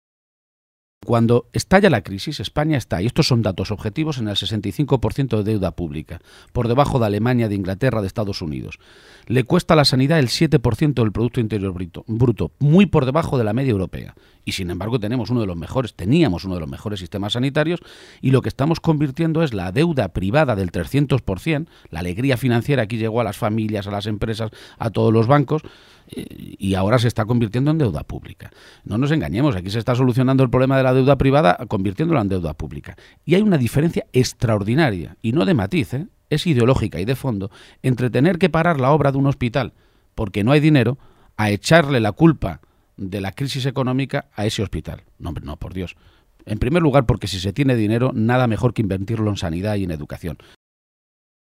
Emiliano García-Page, durante su entrevista en RNE
Cortes de audio de la rueda de prensa